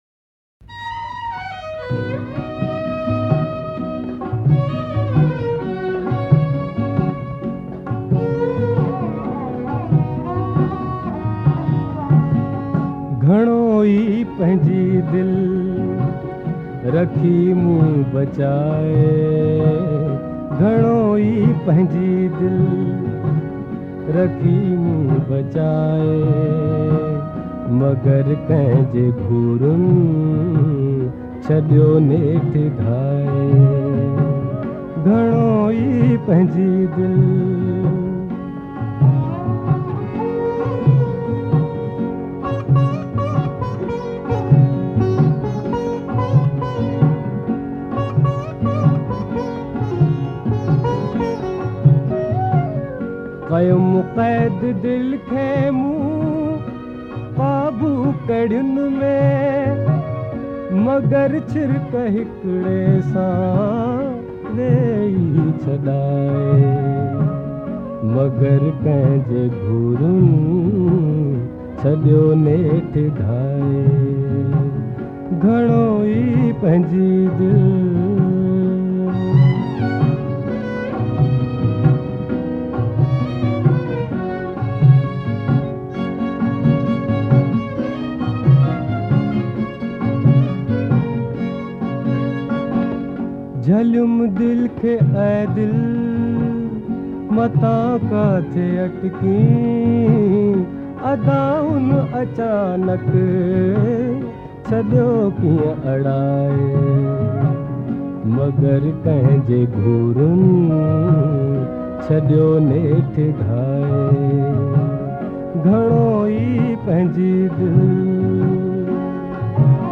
Sindhi Songs